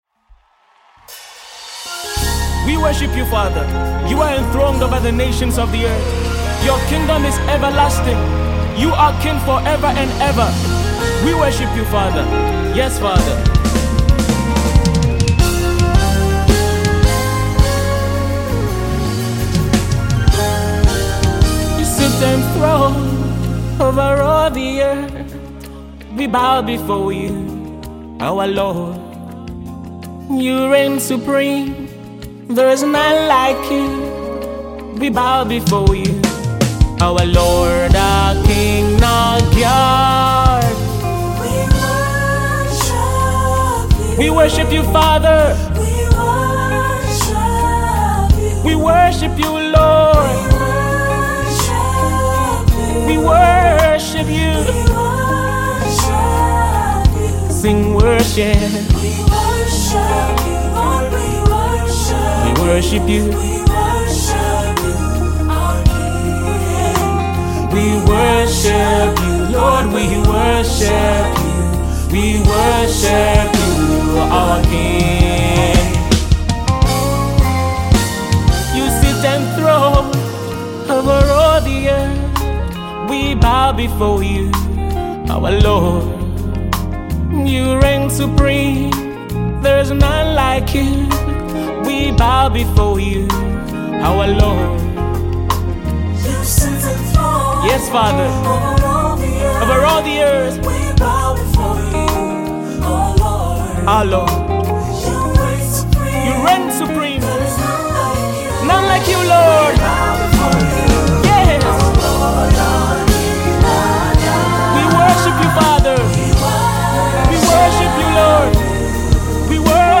Gospel singer
contemporary worship song